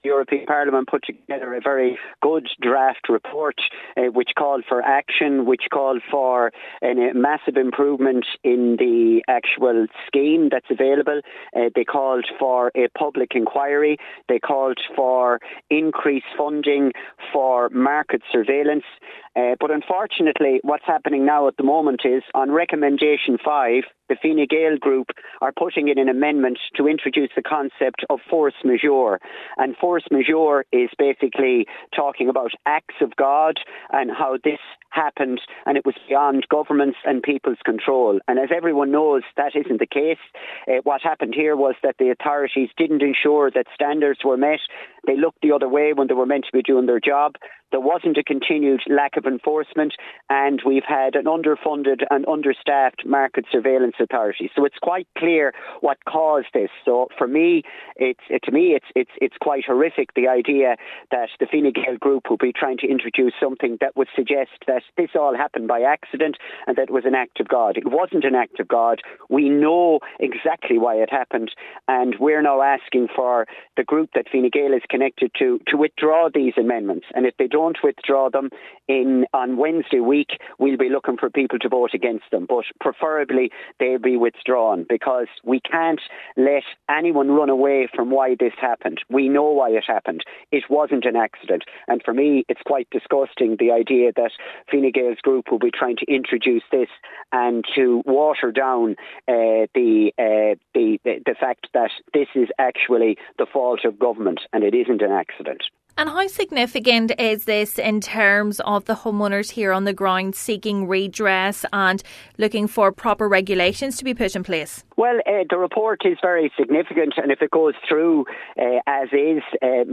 MEP Luke ‘Ming’ Flanagan is highly critical of the move: